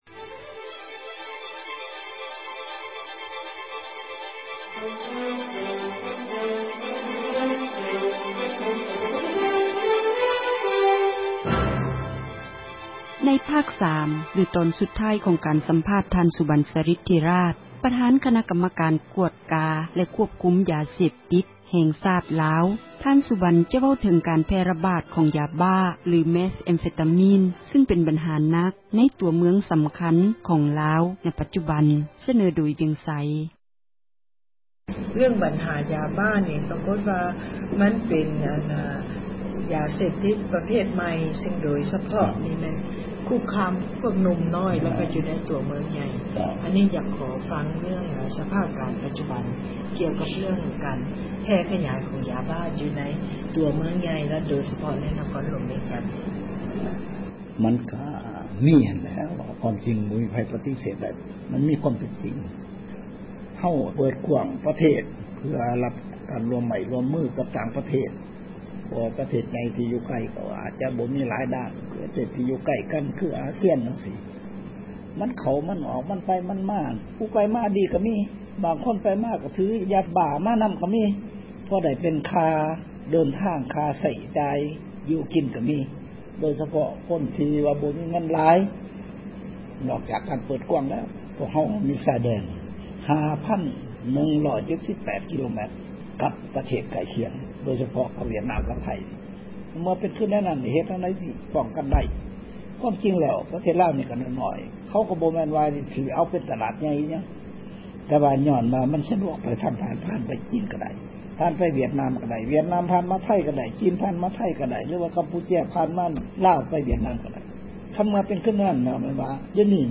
ສັມພາດ ທ່ານ ສຸບັນ ສາຣິດທິຣາຊ (ຈົບ)